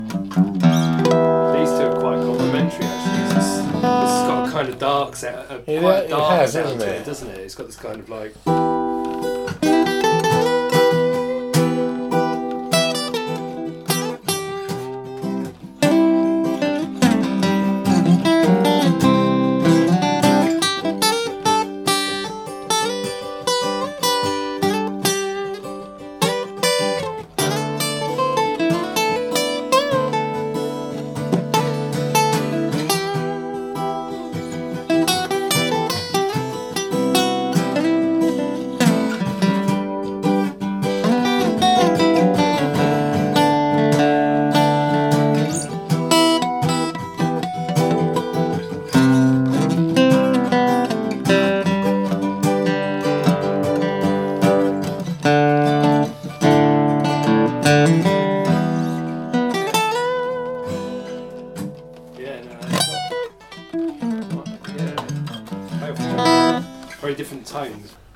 a bit of playing from me
impromptu-acoustic-guitar-jam.mp3